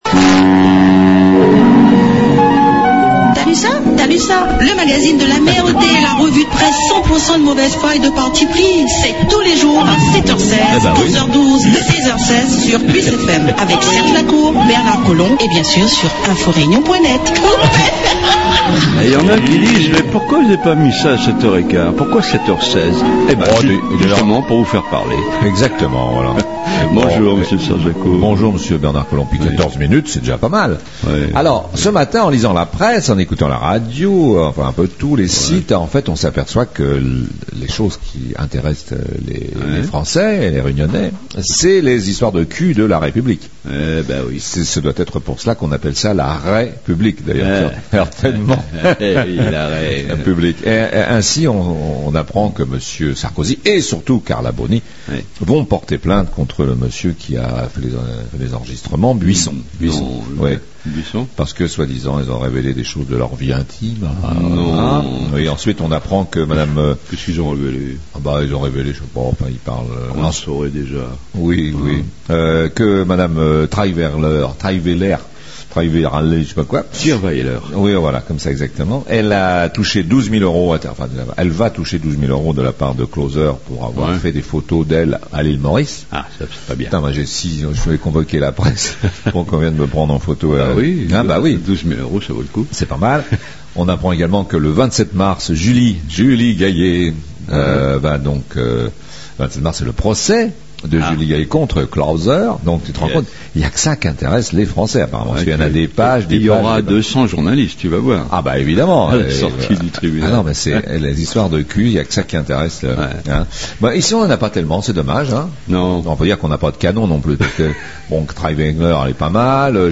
REVUE DE PRESSE